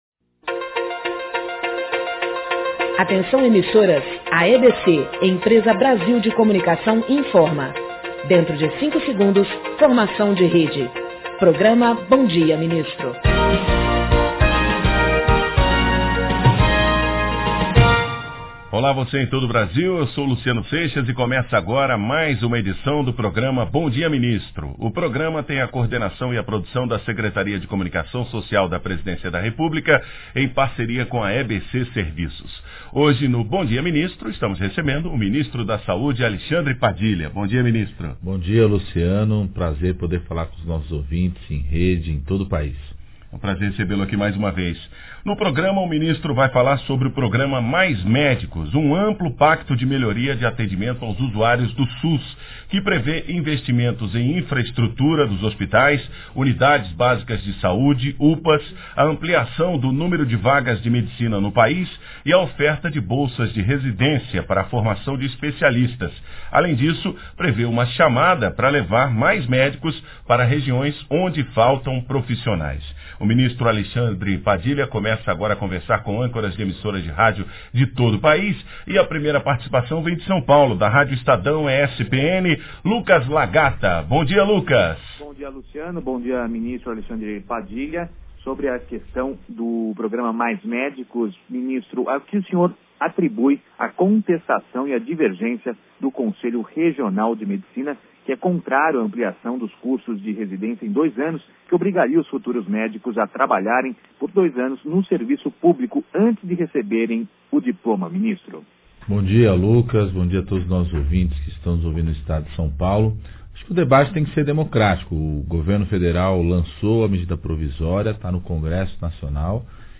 O Bom Dia Ministro que vai ao ar nesta quarta-feira (10/7) recebe o ministro da Saúde, Alexandre Padilha, que fala sobre o programa Mais Médicos. A entrevista é produzida e coordenada pela Secretaria de Comunicação Social da Presidência da República e transmitida ao vivo pela NBR TV e via satélite, das 8h às 9h.